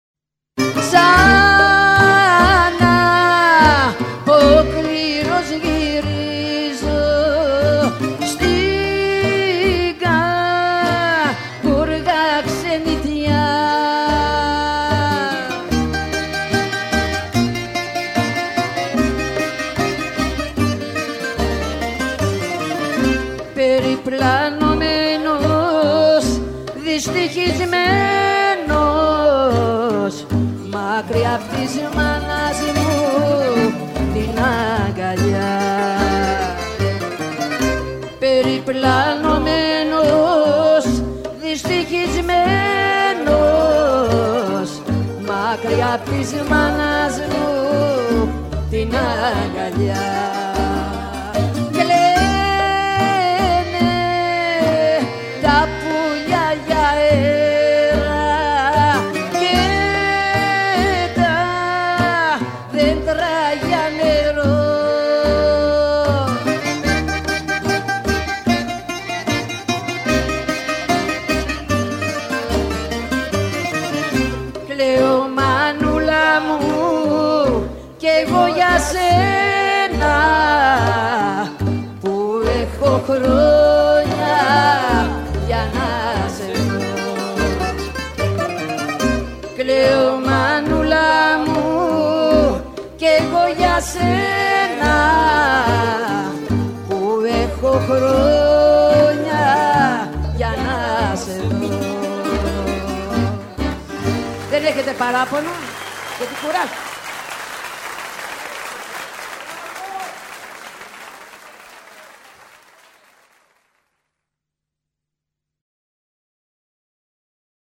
מוסיקת עולם.. נסו לנחש מאיפה זה:)